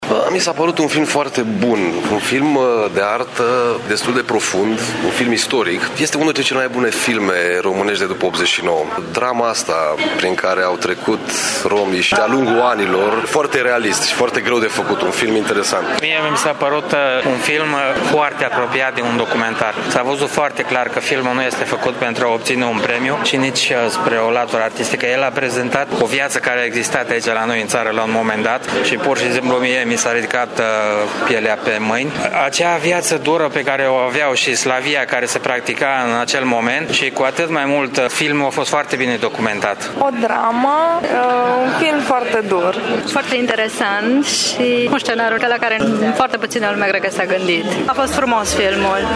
Târgumureșenii au apreciat în mod deosebit acest film pe care îl consideră ca fiind unul dintre cele mai bune producții românești de după 1989: